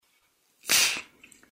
Звуки жвачки